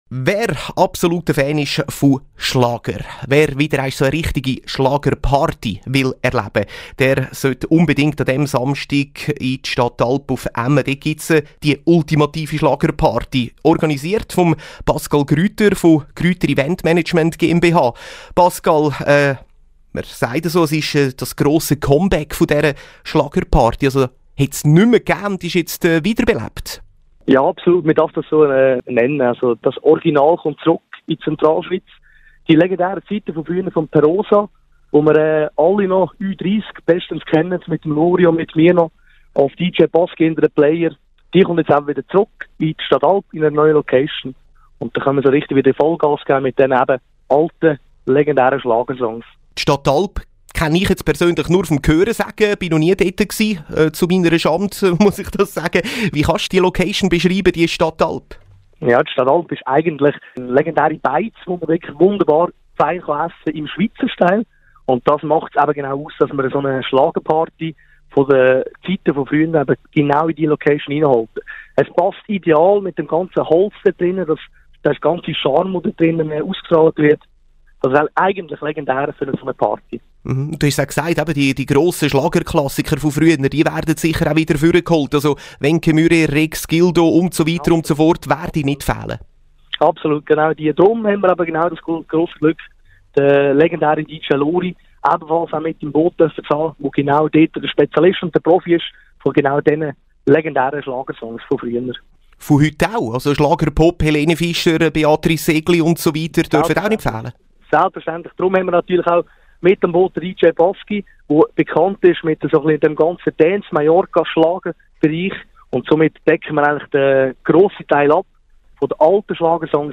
Interview auf Radio Central | GRÜTER EVENTMANAGEMENT GMBH
Gerne präsentieren wir euch das Interview welches am letzten Dienstag, 21. November 2017 auf Radio Central ausgestrahlt wurde.